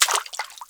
Index of /90_sSampleCDs/AKAI S6000 CD-ROM - Volume 6/Human/FOOTSTEPS_2
WATER 4.WAV